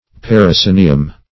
Search Result for " parascenium" : The Collaborative International Dictionary of English v.0.48: Parascenium \Par`a*sce"ni*um\, n.; pl.